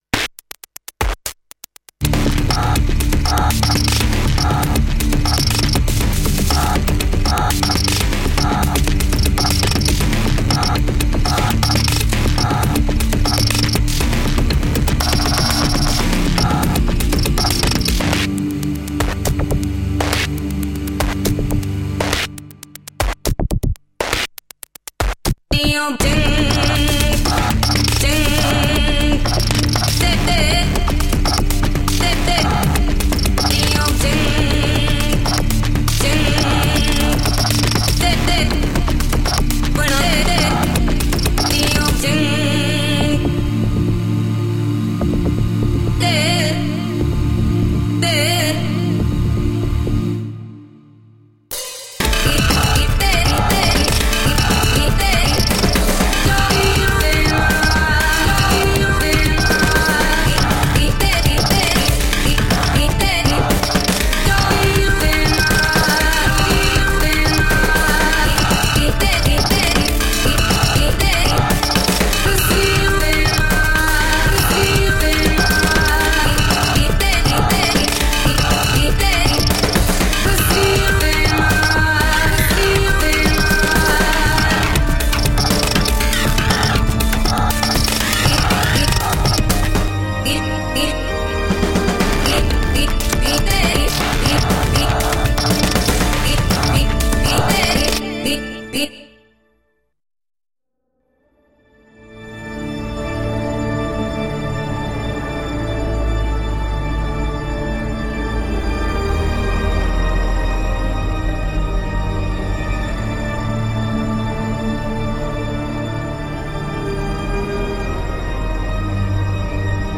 Vivid world-electronica grooves.